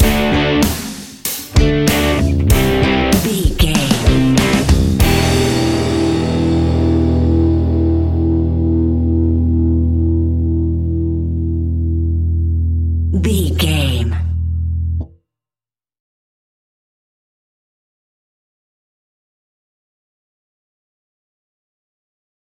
Aeolian/Minor
energetic
driving
heavy
aggressive
electric guitar
bass guitar
drums
hard rock
heavy metal
distortion
distorted guitars
hammond organ